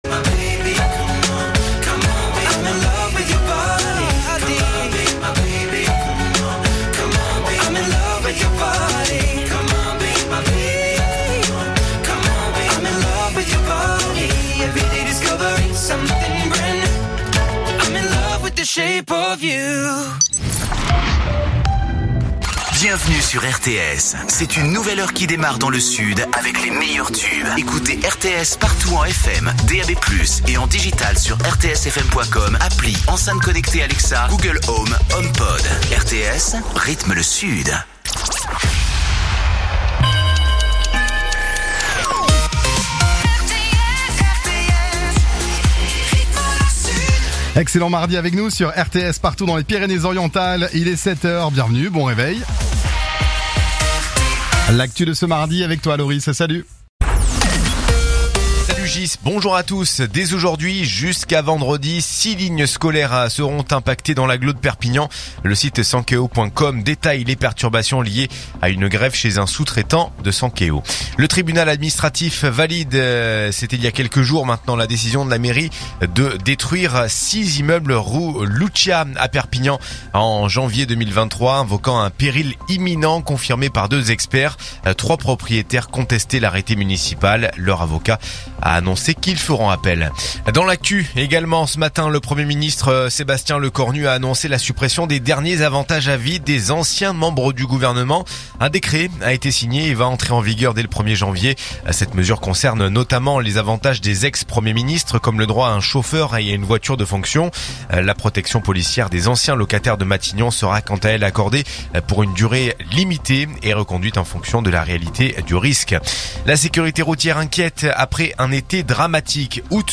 info_perpignan_508.mp3